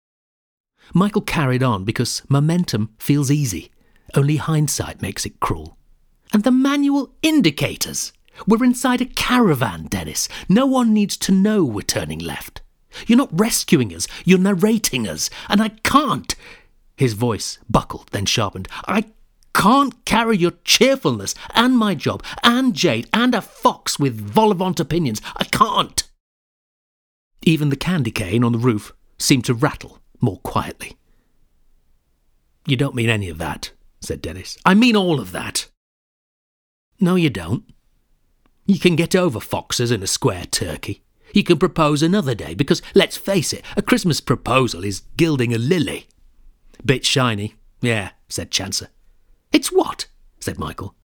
Comedy Drama audiobook narration
Warm storytelling from my Audible titles